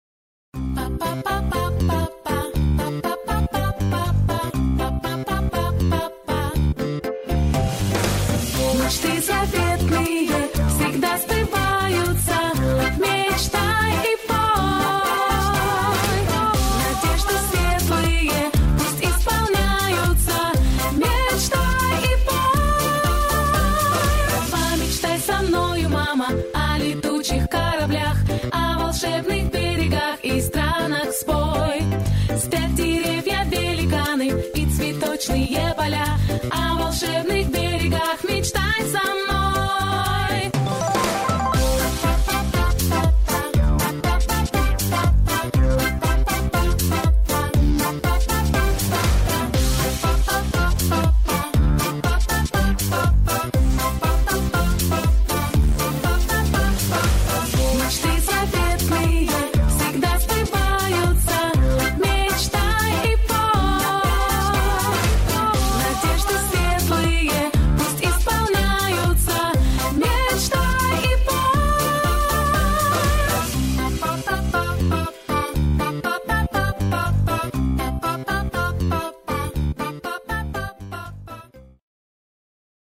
• Категория: Детские песни
🎶 Детские песни / Песни из мультфильмов